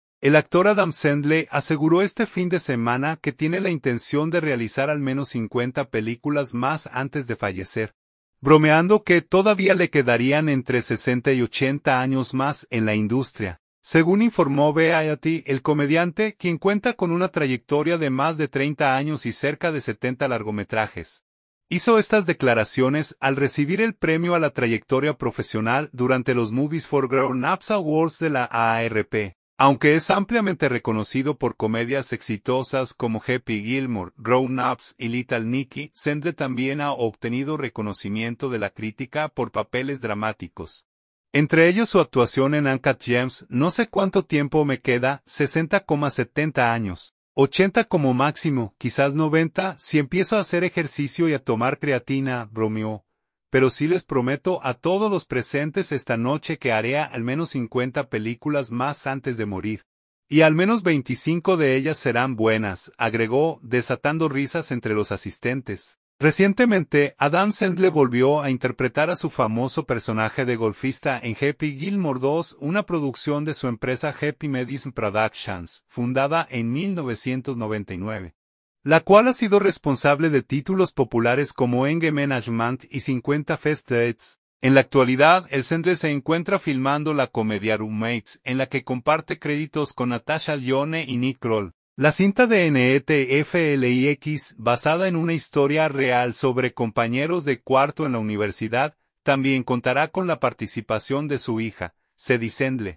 Adam Sandler hizo estas declaraciones al aceptar el premio a la Trayectoria Profesional en los Premios Movies for Grownups de la AARP